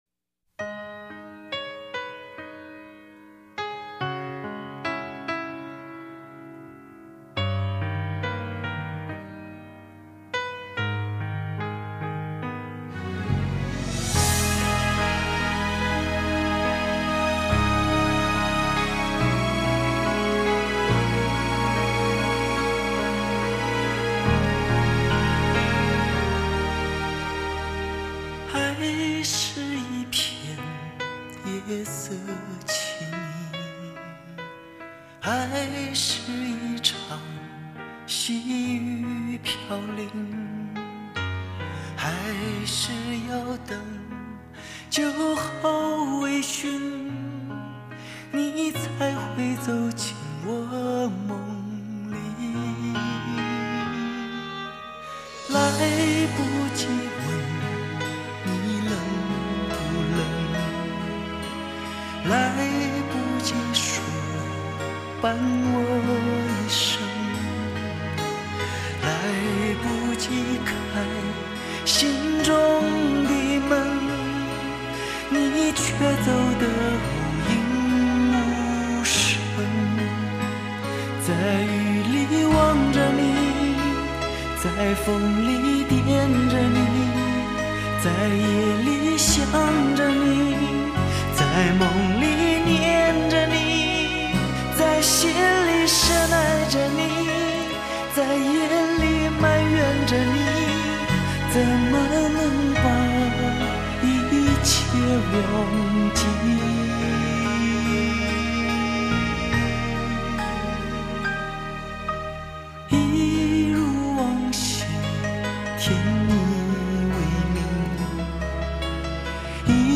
音乐类型：华语流行/男歌手